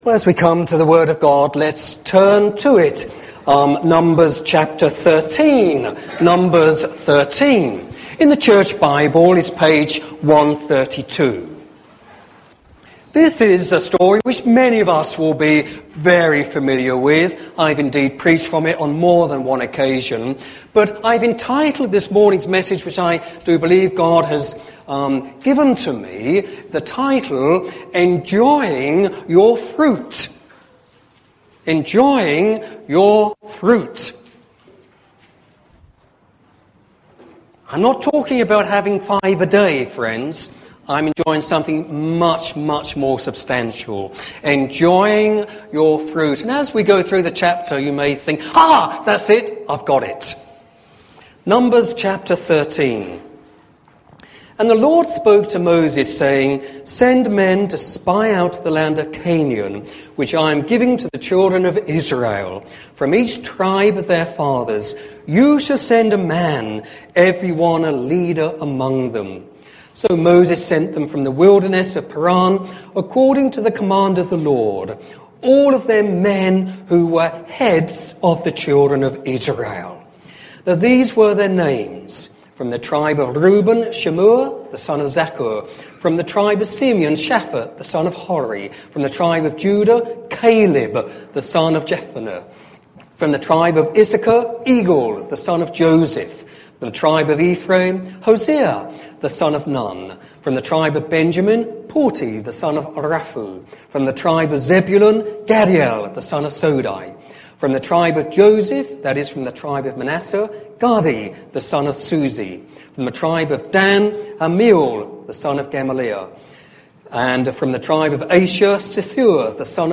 Sermon on God's Plan for Israel